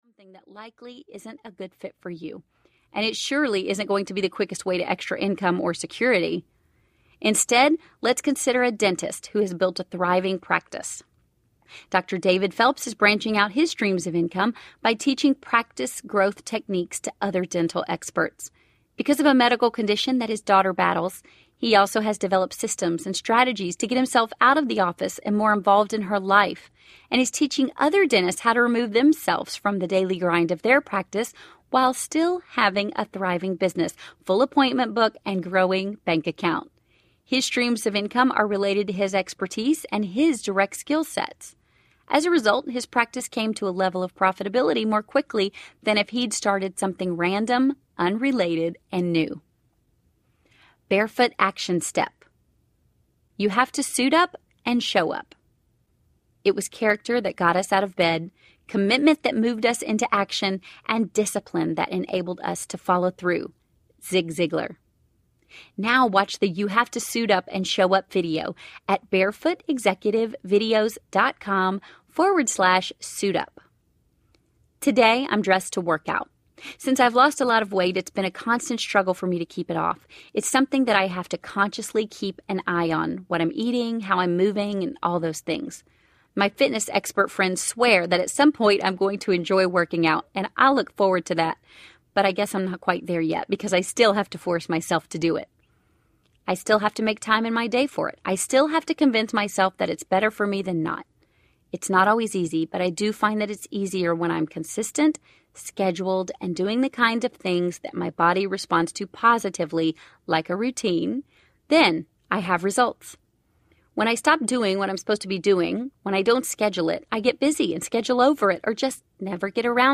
The Barefoot Executive Audiobook
Narrator
6.45 Hrs. – Unabridged